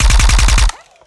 Added more sound effects.
GUNAuto_RPU1 Burst_01_SFRMS_SCIWPNS.wav